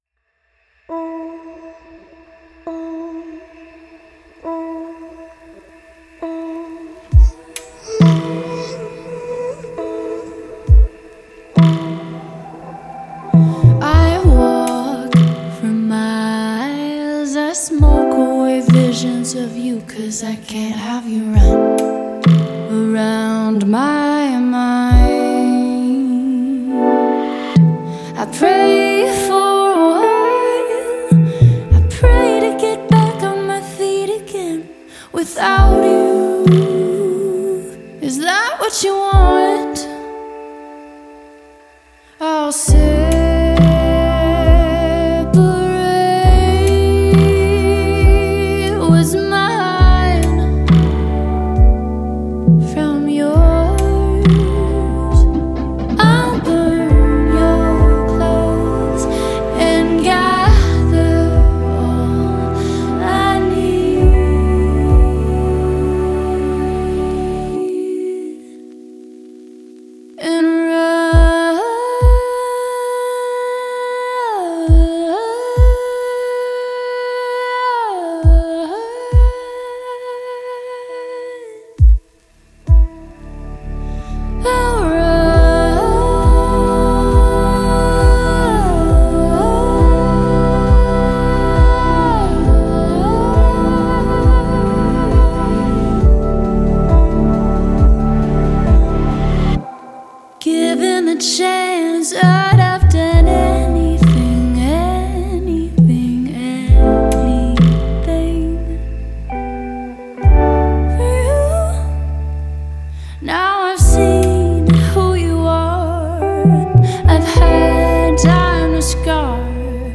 Vocals
Guitar
Keys
Drums